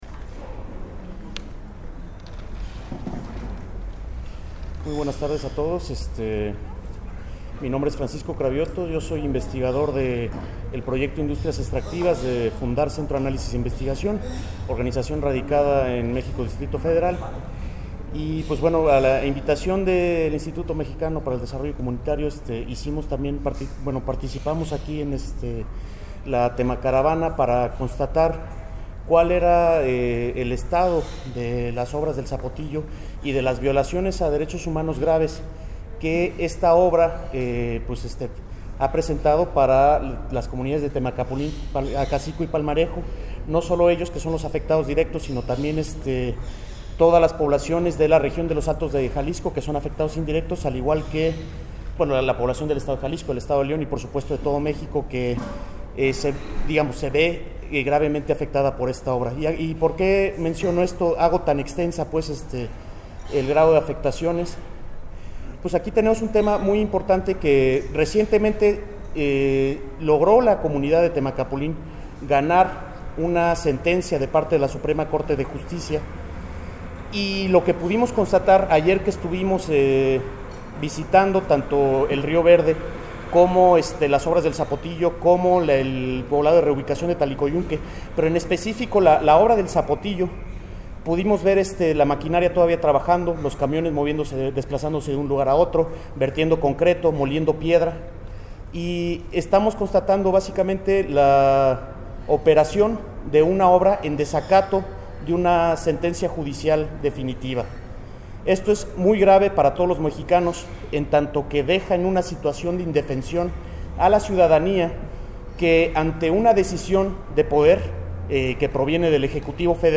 En conferencia de prensa se dio a conocer el resultado de la Auditoría popular a la presa el Zapotillo que afectará a las comunidades de Temacapulin, Palmarejo y Acasico en la que se señala la violación sistemática a los Derechos Humanos, las irregularidades en la construcción de la presa el Zapotillo, el posible desacato de la sentencia emitida por la Suprema Corte de Justicia de la Nación (SCJN), el mal manejo del agua en el estado de Jalisco y se exigió al presidente Enrique Peña Nieto cumplir los derechos de los pueblos originarios dejando de lado la política extractivista que tiene consecuencias irreparables al medio ambiente.